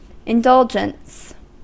Ääntäminen
IPA : /ɪnˈdʌld͡ʒəns/